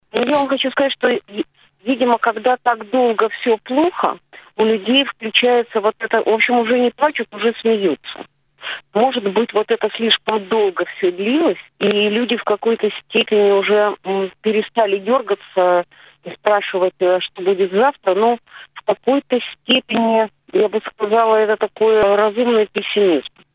На радио Baltkom сегодня журналисты обсуждали несколько тем - как общество спасается от депрессии, как полиция борется с кибермошенниками и что делает парламентская оппозиция в период принятия решение по COVID.